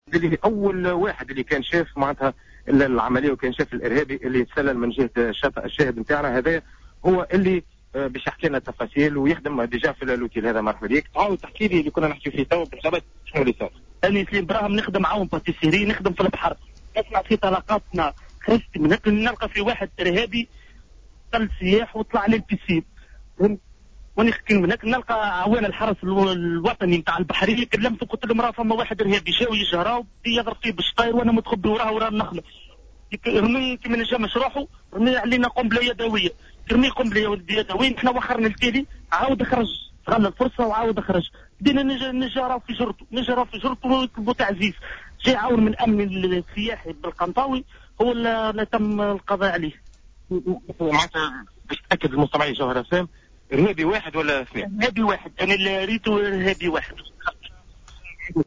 شاهد عيان يروي تفاصيل الهجوم الارهابي على نزل إمبريال بالقنطاوي